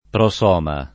[ pro S0 ma ]